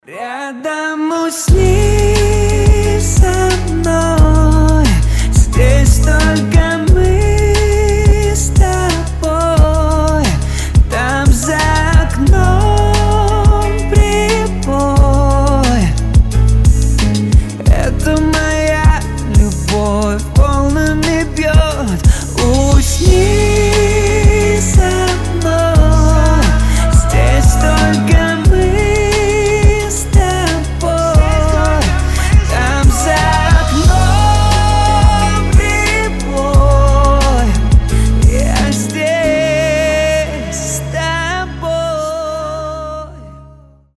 • Качество: 320, Stereo
мужской голос
спокойные
медленные
нежные